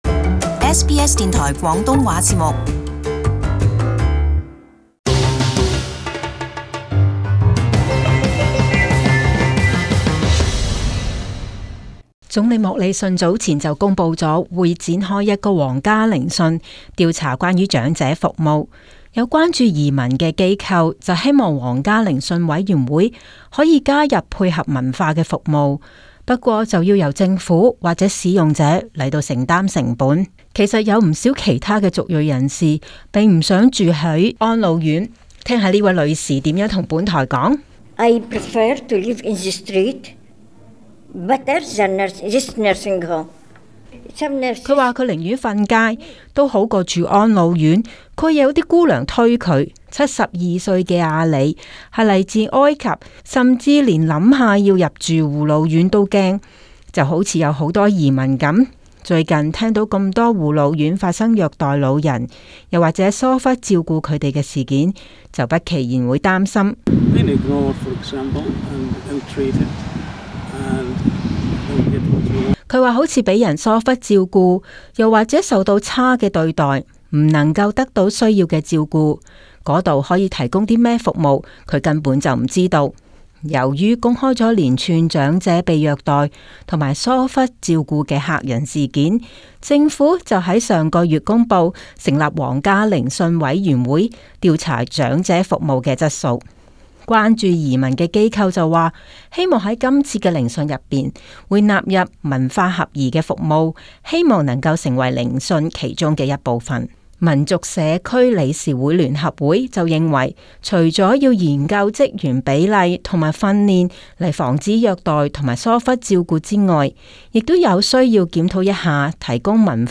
【時事報導】具文化配合的長者服務